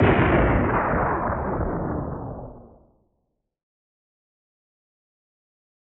Roland.Juno.D _ Limited Edition _ GM2 SFX Kit _ 10.wav